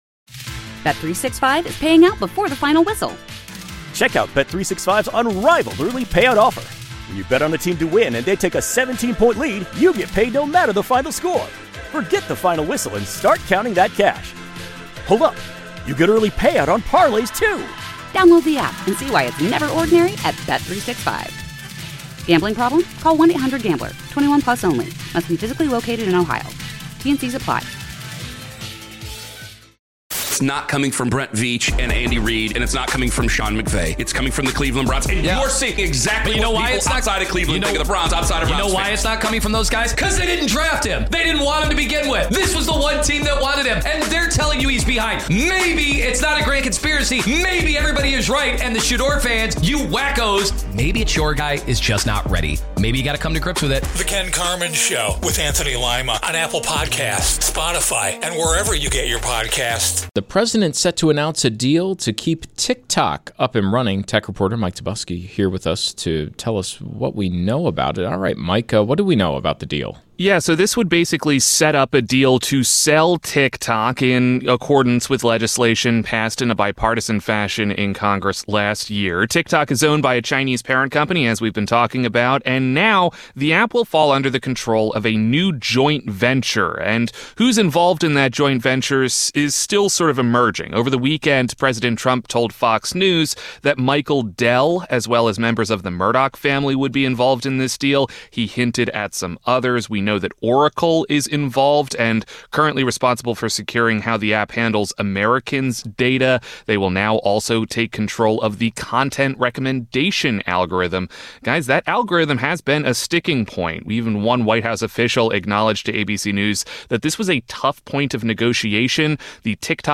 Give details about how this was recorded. Collection of LIVE interviews from Buffalo's Early News on WBEN